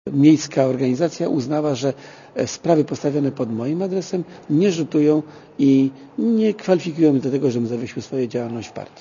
W Płocku z niektórymi działaczami SLD rozmawiał reporter Radia Zet.